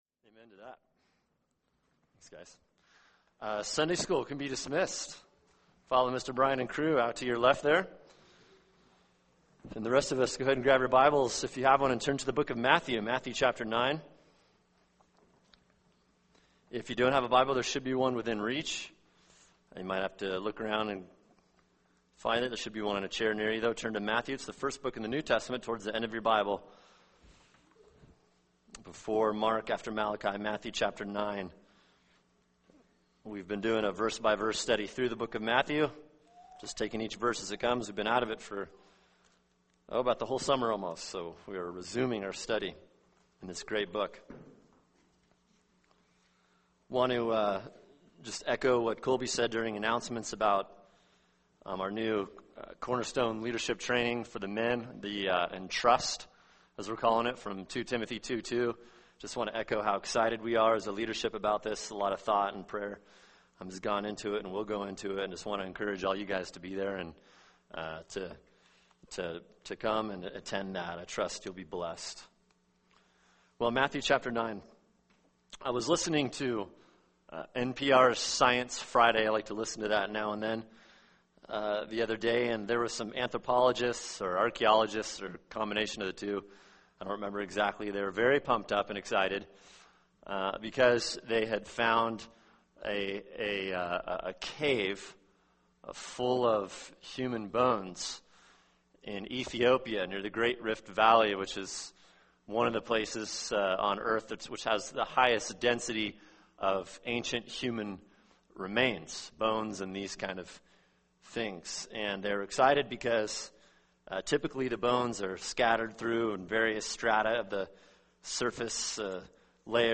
[sermon] Matthew 9:18-26 “Hope for Suffering” | Cornerstone Church - Jackson Hole